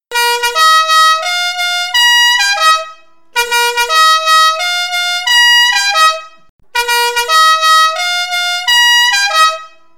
klaxon italien la Marseillaise, l'hymne national à jouer pour les victoires de vos équipes favorites, puissance et fiabilité pour ce klaxon à compresseur
Klaxon La Marseillaise 5 trompes ABS rouge 118dB à 2 mètres.
Klaxon italien La Marseillaise, 5 trompes ABS et compresseur. 118dB à 2 mètres.
son-la-marseillaise.mp3